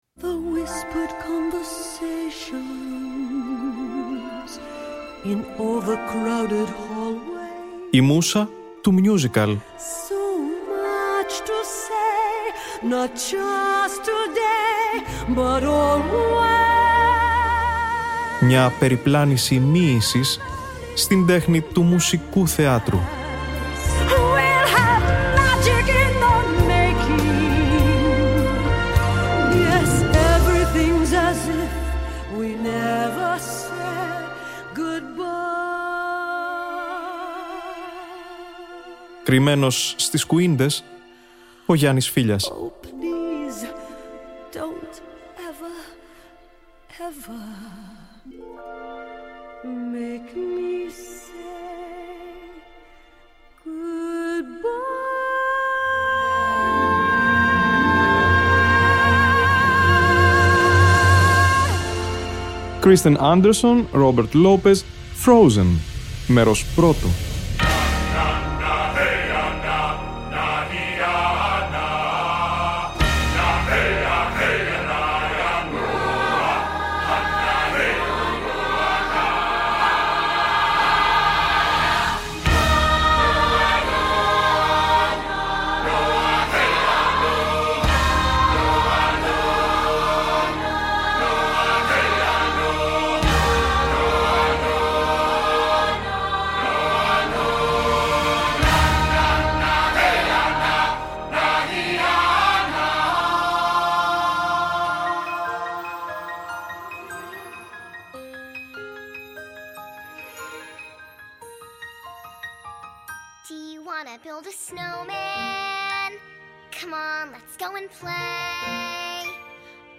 Broadway musical